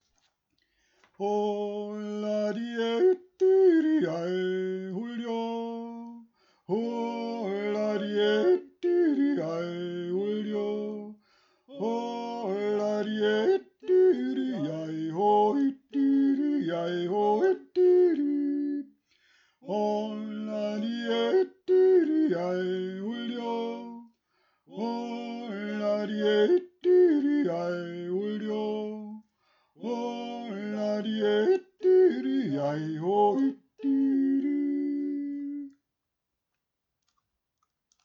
1. Stimme